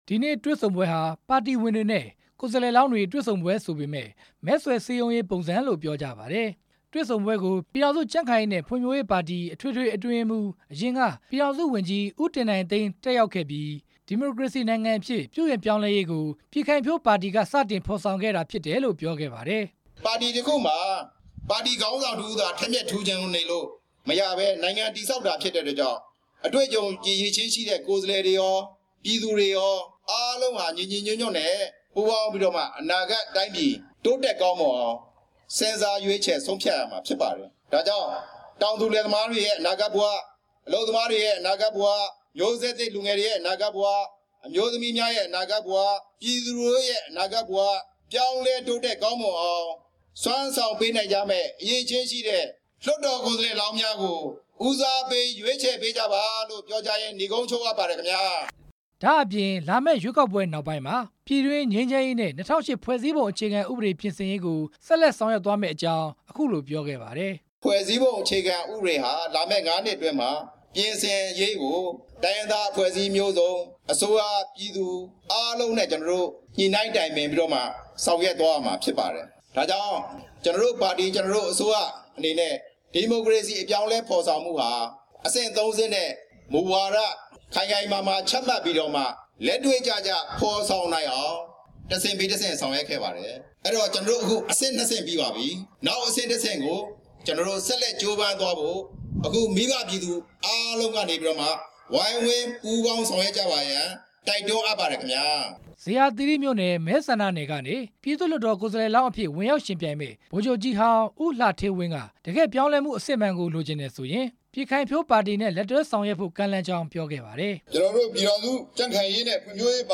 နေပြည်တော်ကောင်စီနယ်မြေ မြို့နယ် ၈မြို့နယ်ကနေ ဝင်ရောက်ယှဉ်ပြိုင်မယ့် ပြည်ခိုင်ဖြိုးပါတီ ကိုယ်စားလှယ်လောင်း ၉ ဦးနဲ့ ပါတီဝင်တွေ တွေ့ဆုံပွဲမှာ ဦးတင်နိုင်သိန်းက ပြောခဲ့တာဖြစ်ပါတယ်။